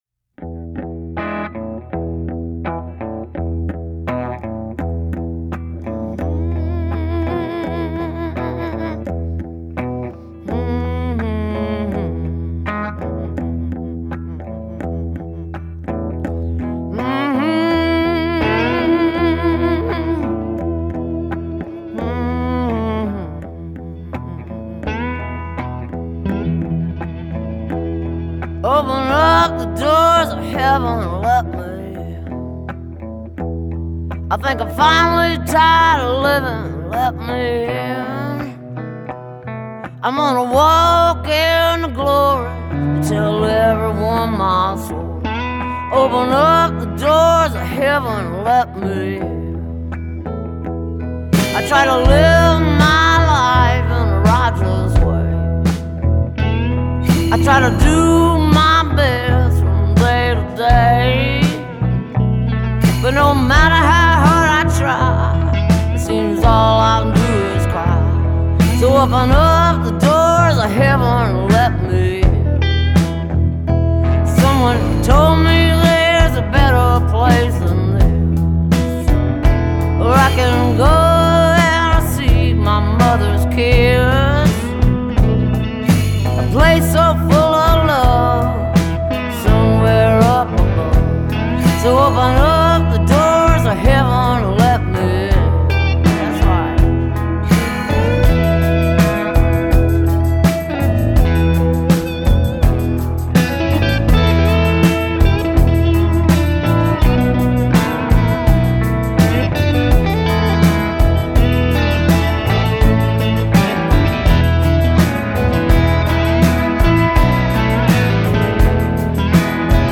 Mercifully less sleepy, but still slurry.